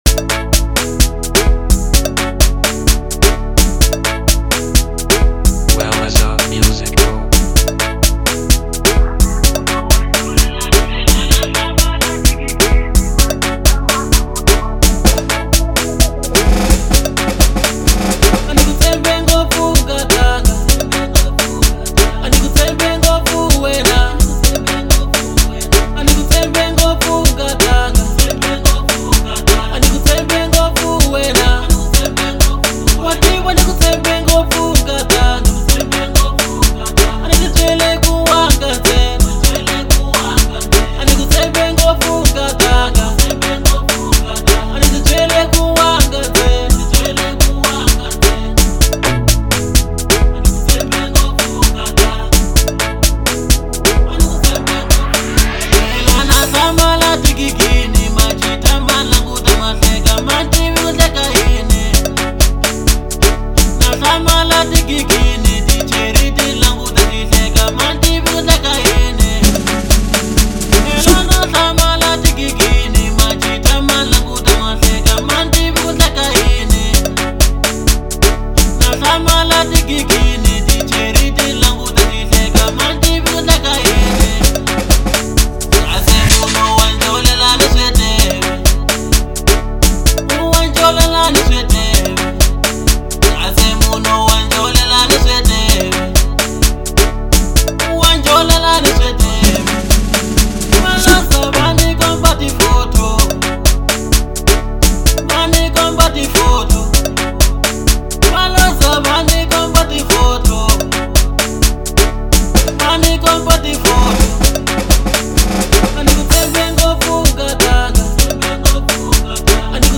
05:21 Genre : Local House Size